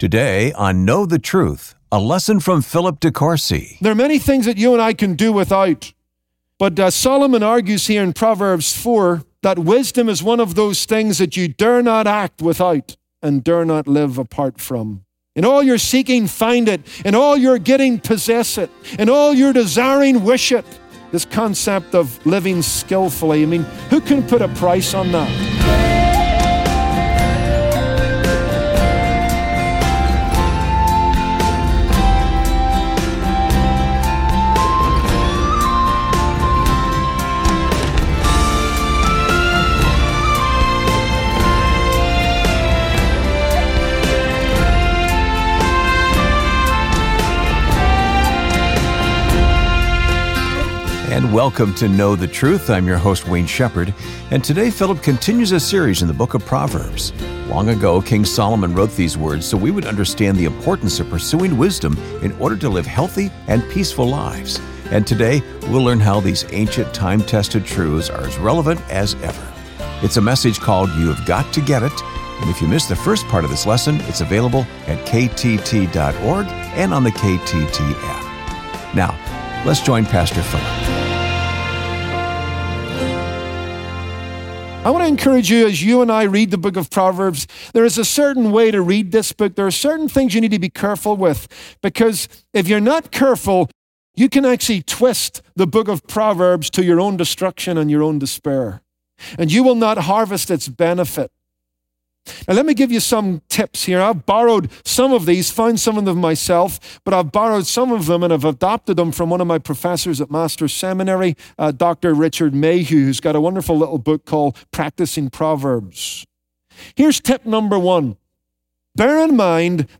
But the Bible says that few virtues will advance your cause any more than wisdom. On this Tuesday broadcast